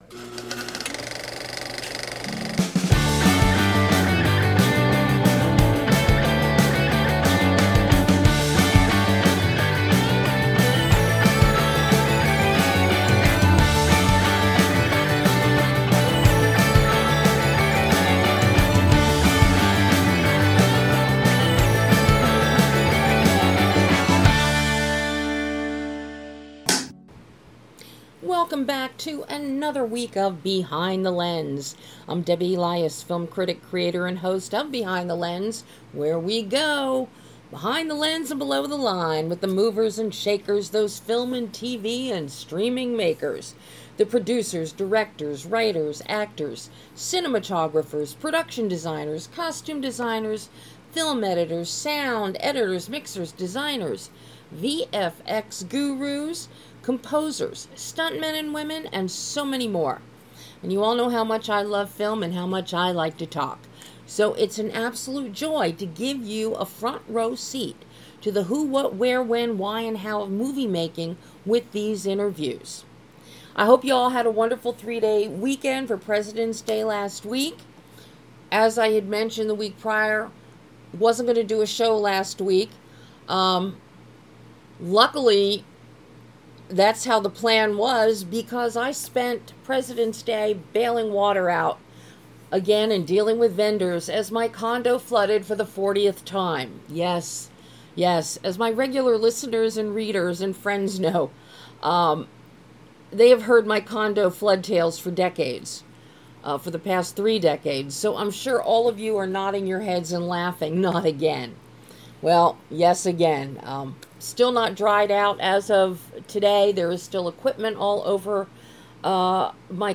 BTL Radio Show - 02/23/2026 with director BRAD ANDERSON discussing WORLDBREAKER